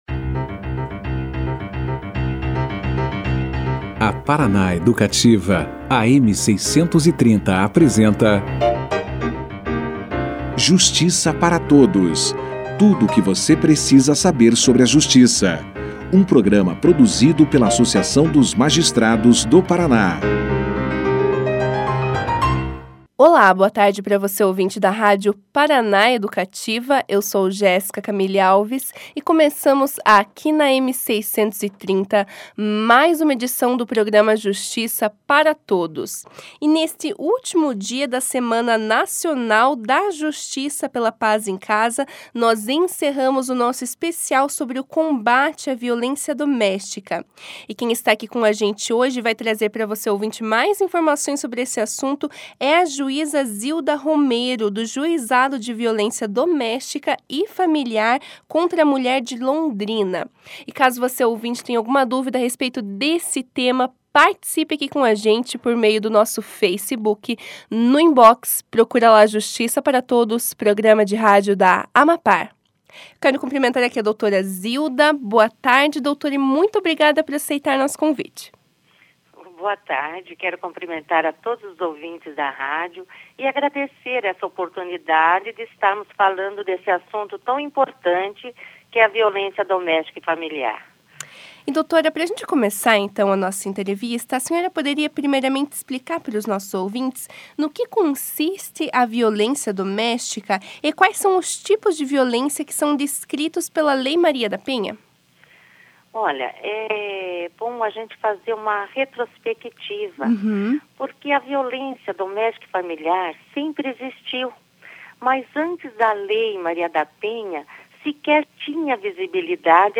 Na sexta-feira (24), último dia da Semana Nacional pela Paz em Casa, o Justiça para Todos conversou com a juíza Zilda Romero, do Juizado de violência doméstica e familiar contra mulher de Londrina, sobre o combate a violência doméstica. Zilda deu início a entrevista explicando no que consiste a violência doméstica, como a mulher pode denunciar seu agressor e qual é a importância dessa denúncia. A magistrada também explicou os tipos de violências previstas pela Lei Maria da Penha como, por exemplo, a violência patrimonial, sexual, psicológica e moral.